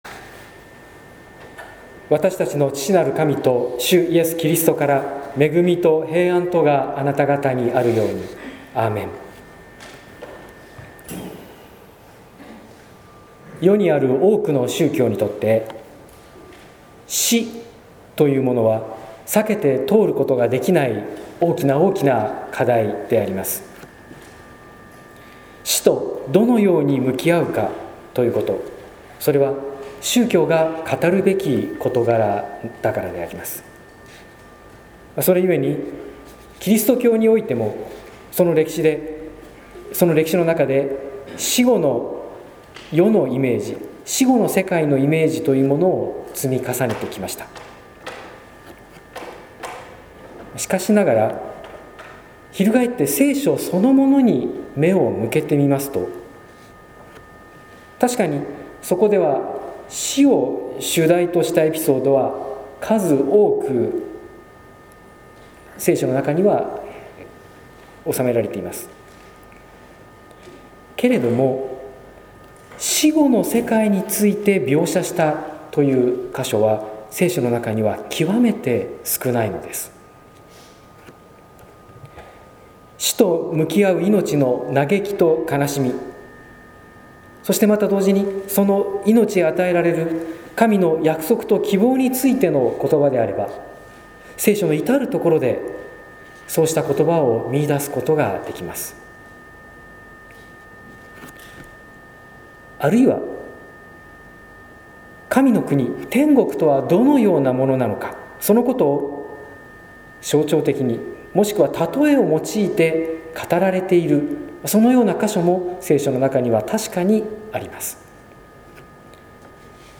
説教「慰めの場を思って」（音声版）